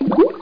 GLUG.mp3